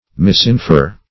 Misinfer \Mis`in*fer"\